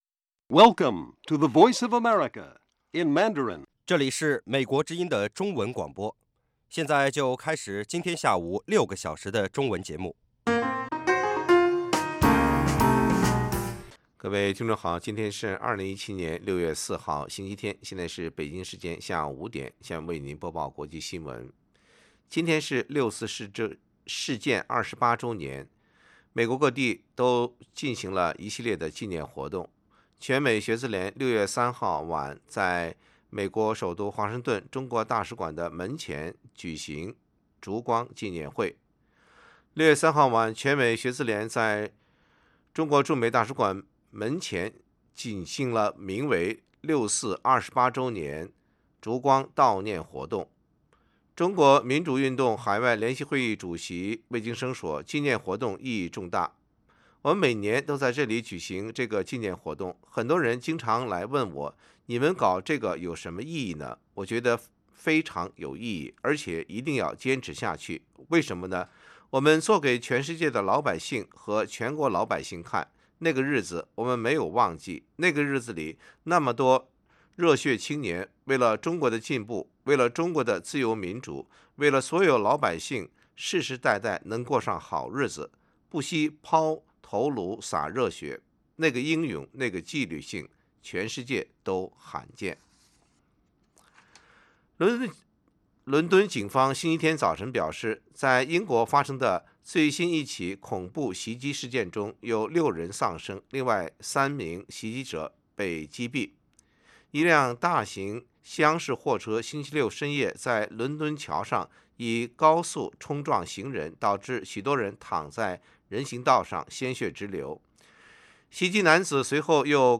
北京时间下午5-6点广播节目。广播内容包括国际新闻，美语训练班(学个词， 美国习惯用语，美语怎么说，英语三级跳， 礼节美语以及体育美语)，以及《时事大家谈》(重播)